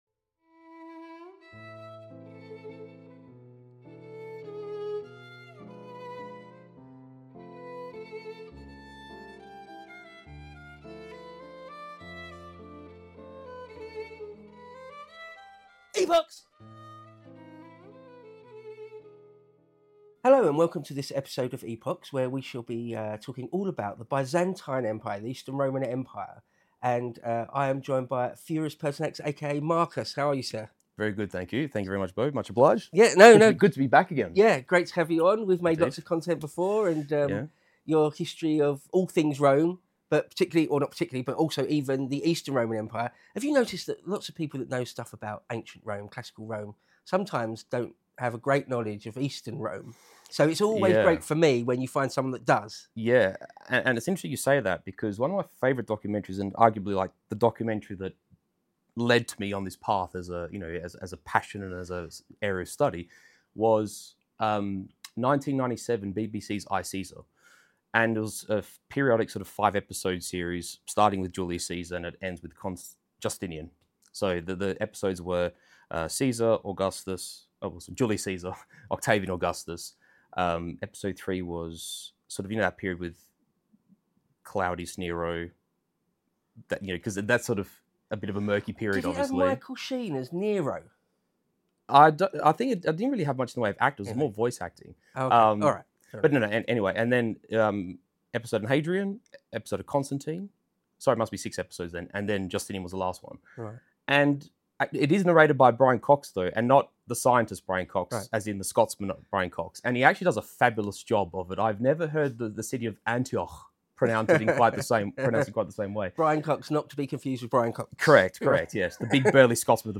A superb conversation all about the Byzantine Eastern Roman Empire, ultimately culminating in the Fourth Crusade.